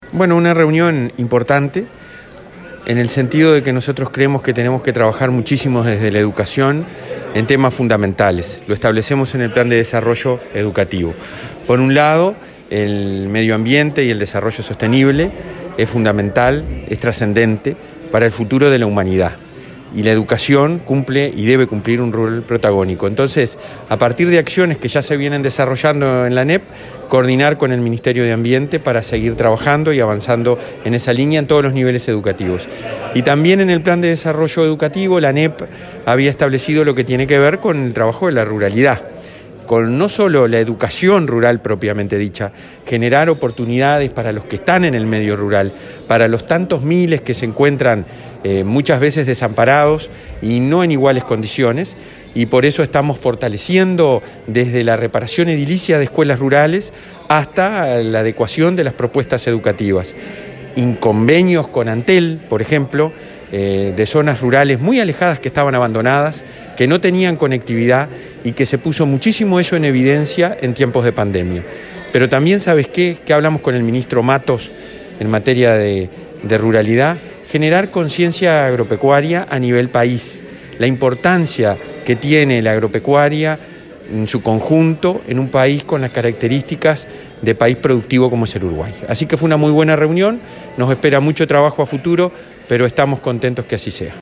Declaraciones del presidente del Codicen, Robert Silva
El presidente del Consejo Directivo Central (Codicen) de la Administración Nacional de Educación Pública (ANEP), Robert Silva, dialogó con